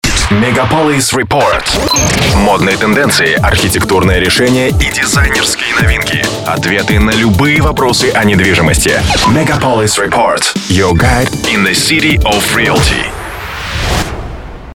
Тракт: продакшн студия радиостанции. продакшн студия рекламного агентства домашний продакшн mic AKG, audio - Lexicon, Sony SF, Cubase